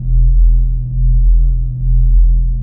sounds_spacewar_weapons.dat
Halalsugar-loop.wav